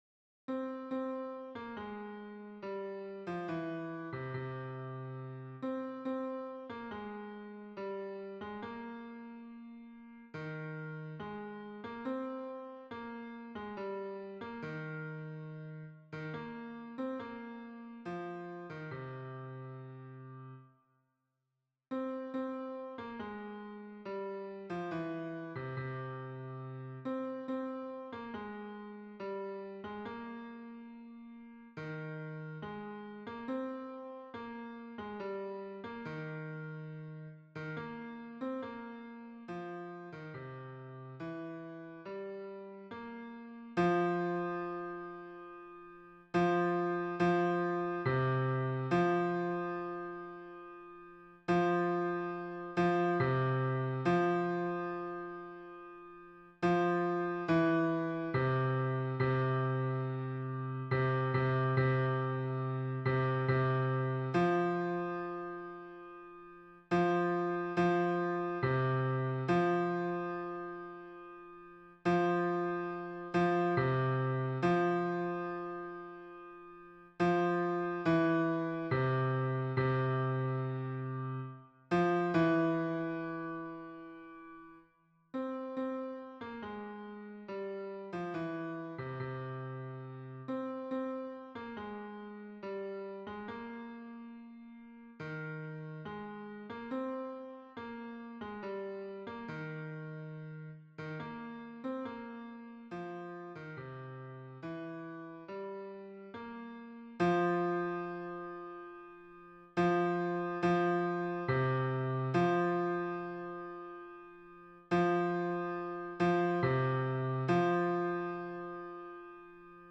MP3 version piano
Hommes